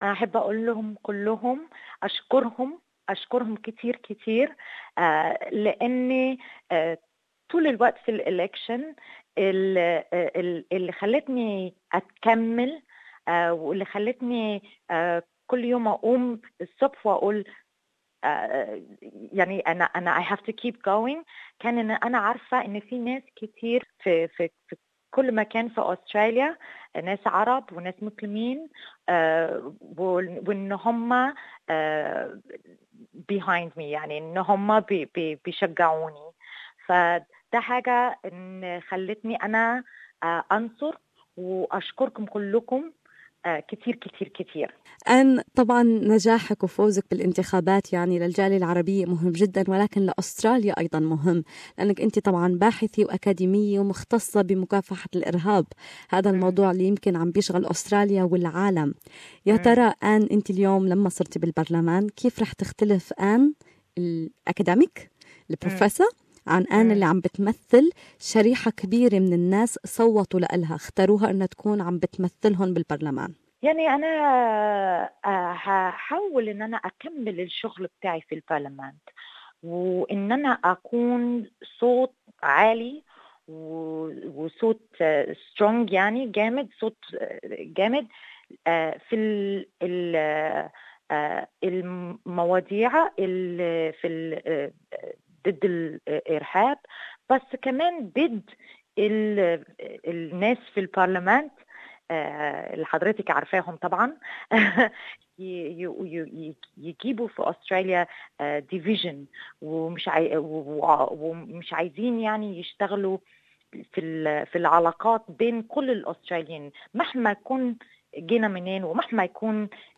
Professor Ann Aly is the first Arabic woman to be elected into the Federal Parliament. With a wealth of experience in counter-extremism she hopes to be the voice of the community in the Australian Political Scene. More in this interview